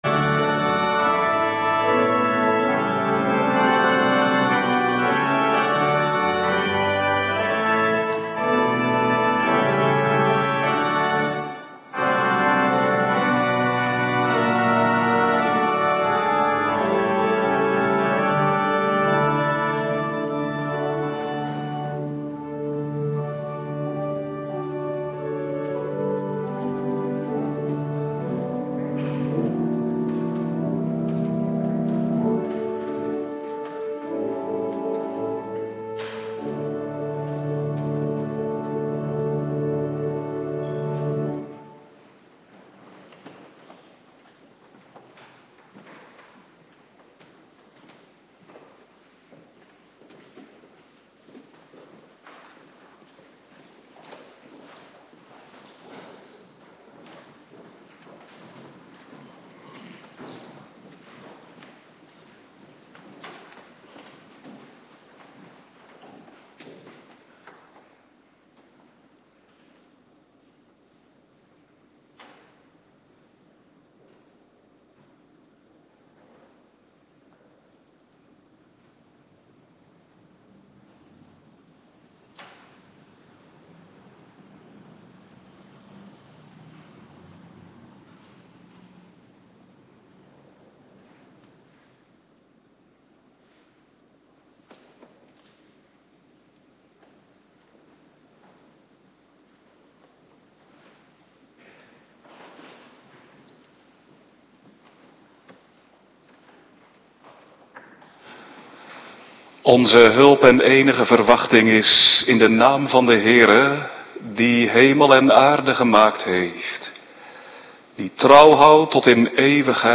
Avonddienst voorbereiding Heilig Avondmaal
Locatie: Hervormde Gemeente Waarder